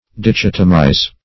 Dichotomize \Di*chot"o*mize\, v. i.